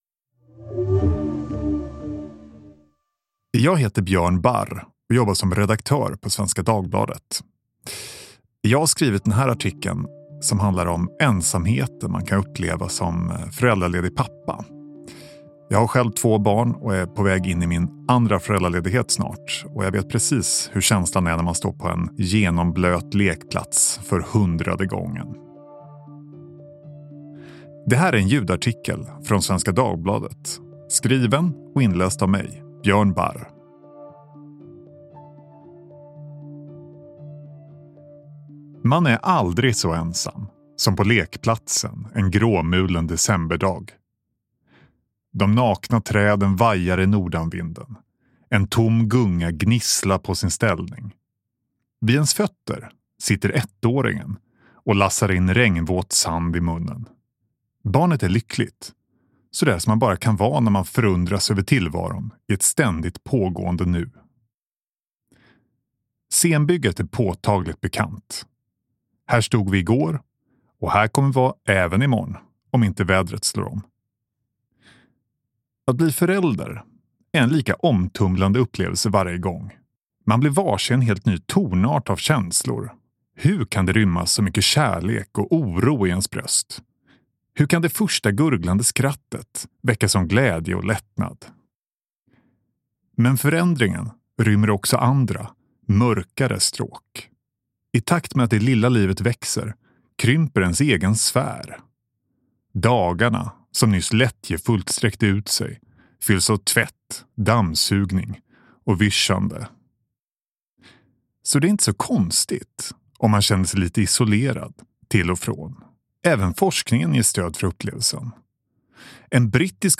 Inläst: Att bli pappa är omvälvande – men det väcker också känslor av ensamhet. Som tur är finns det en osviklig metod för att bryta isoleringen.